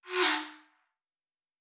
pgs/Assets/Audio/Sci-Fi Sounds/Movement/Fly By 02_2.wav at master
Fly By 02_2.wav